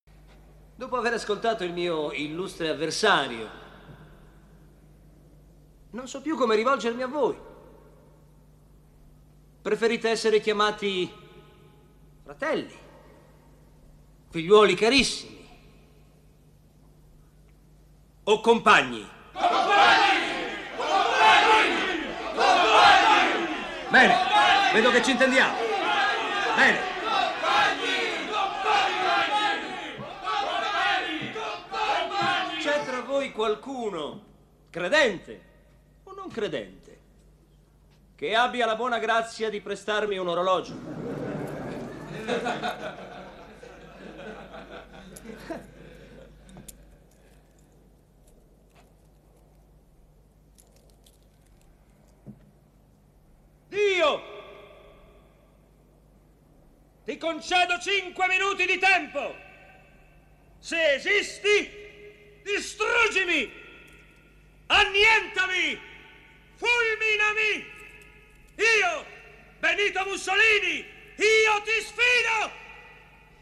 nella miniserie "Il giovane Mussolini", in cui doppia Antonio Banderas.